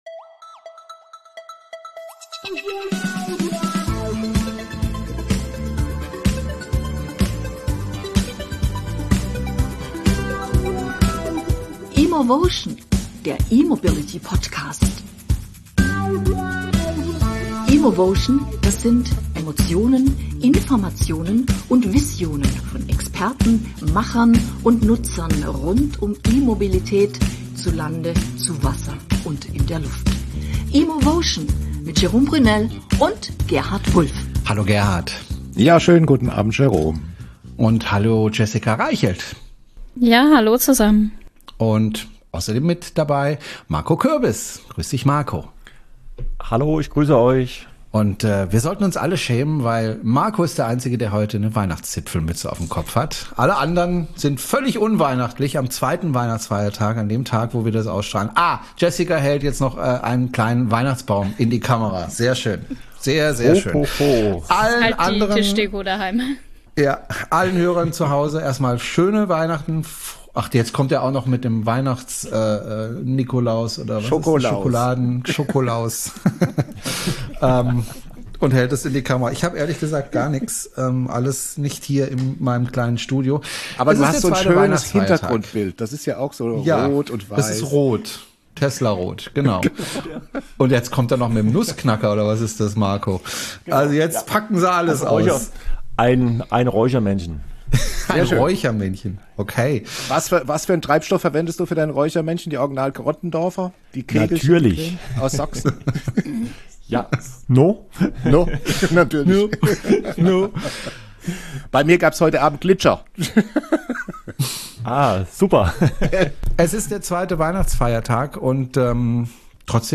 Diesmal sprechen wir in lockerer Runde über das was war, das was ist und das was sein wird…